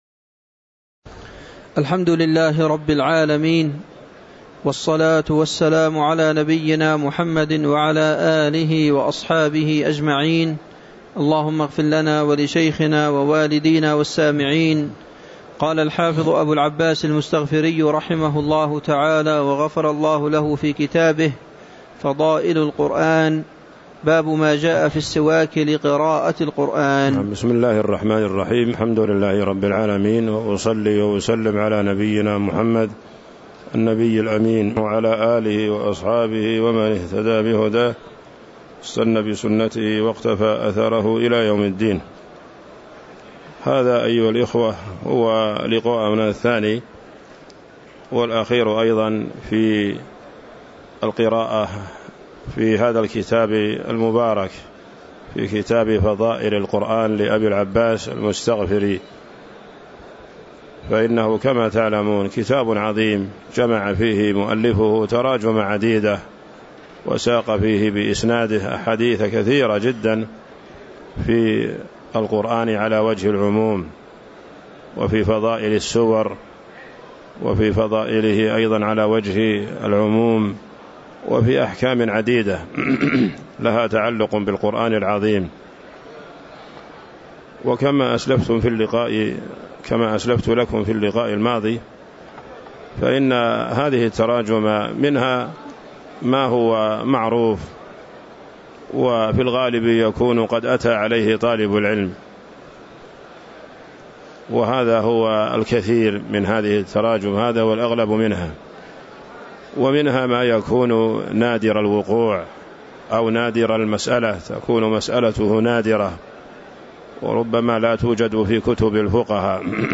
تاريخ النشر ٤ رمضان ١٤٤٥ هـ المكان: المسجد النبوي الشيخ